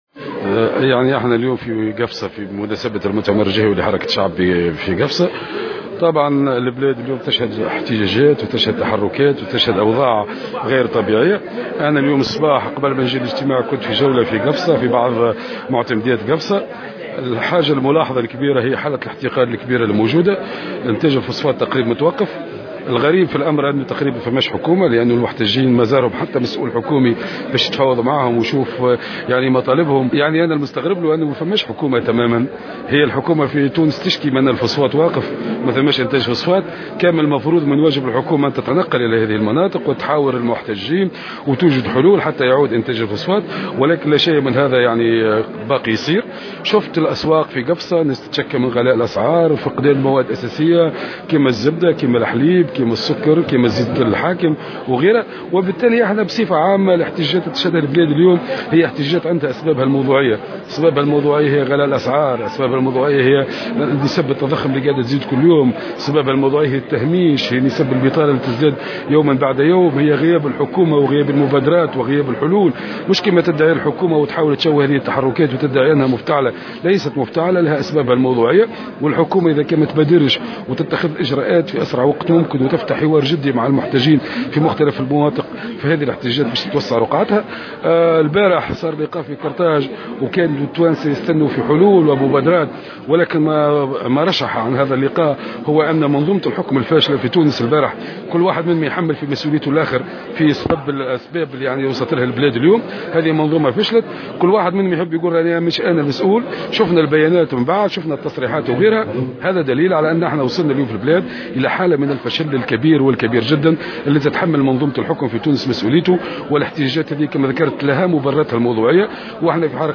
وحذّر المغزاوي، في تصريح لمراسل الجوهرة اف أم، على هامش فعاليات المؤتمر الجهوي لحركة الشعب بقفصة، اليوم السبت، من اتساع رقعة هذه الاحتجاجات في حال تواصل تجاهل الحكومة للمطالب المرفوعة من قبل المحتجين، معتبرا أن تواصل التحركات الاحتجاجية في الحقل المنجمي مرده حالة الاحتقان التي تشهدها المنطقة وسط غياب تام للسلطات.